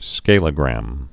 (skālə-grăm)